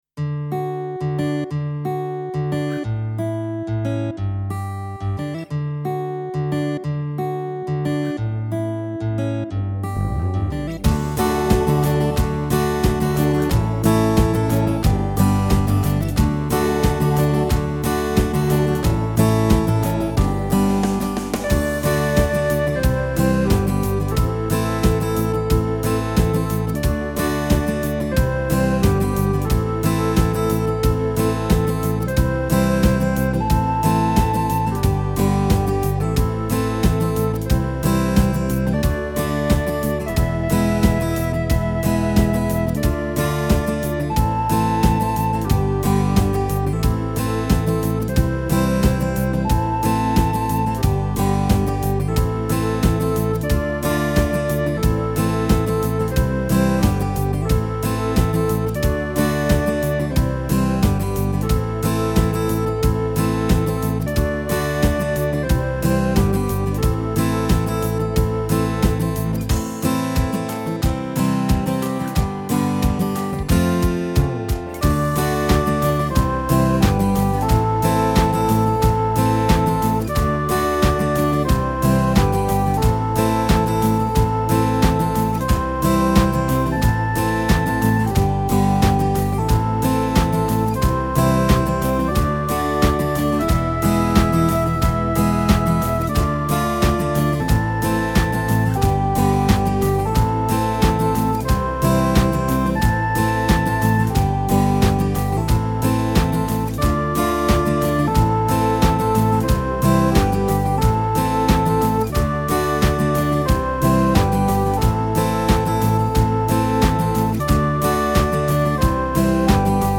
Home > Music > Pop > Bright > Folk > Medium